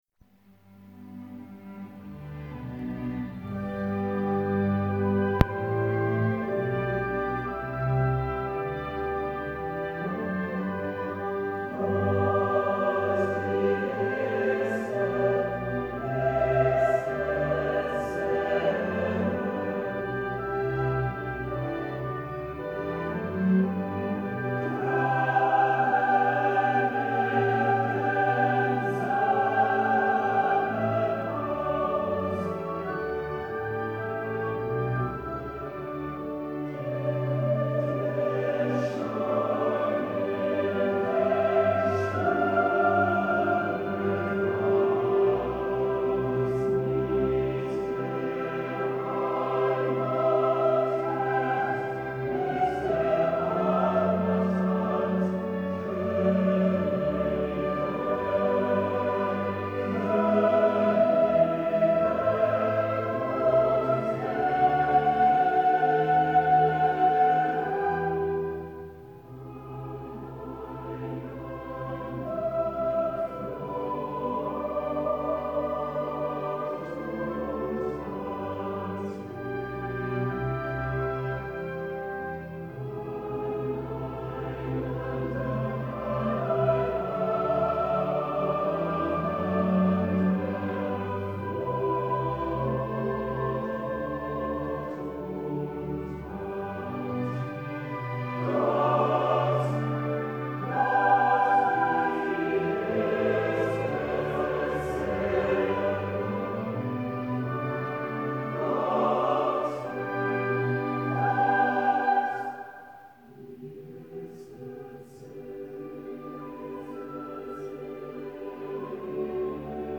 This month I discovered such a beautiful (but rather unknown) chorus with orchestra that I edited it into my notation system just to study especially the rich handling of the woodwinds.
You should recognise the composer by listening to the original (not best soundquality):